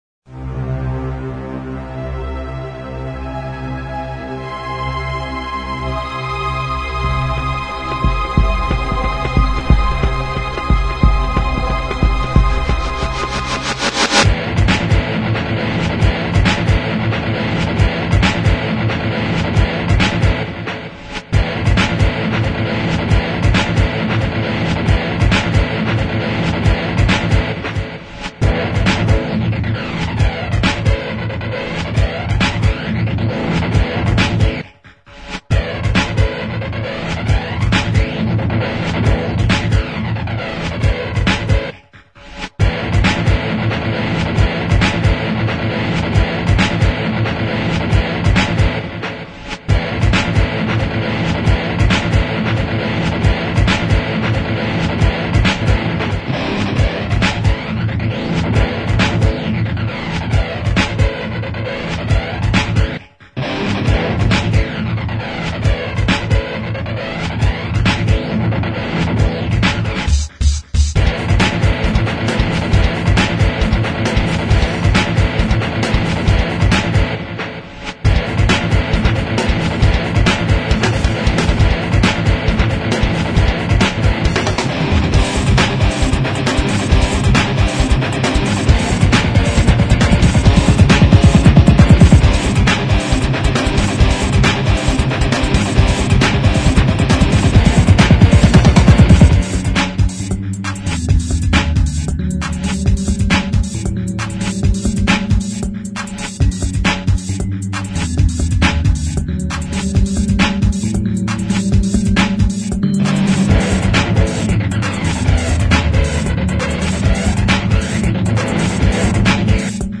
• Жанр: Альтернативная
Инструментальная версия трека